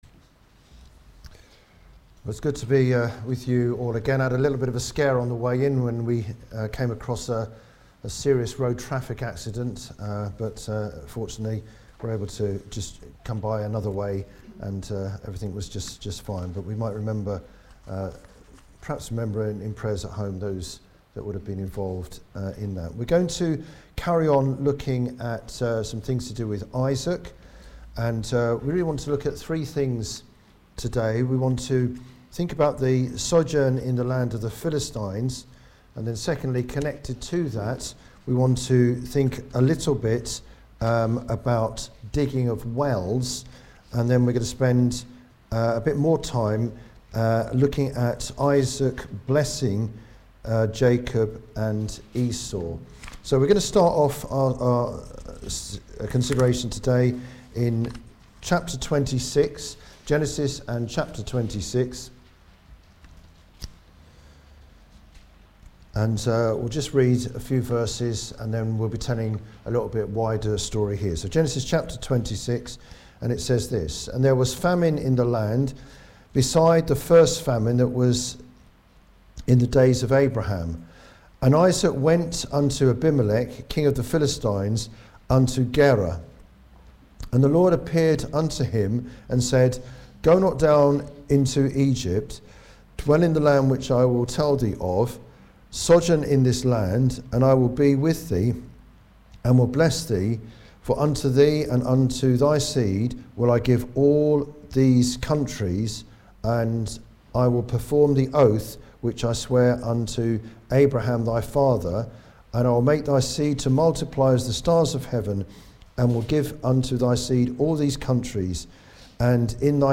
Service Type: Ministry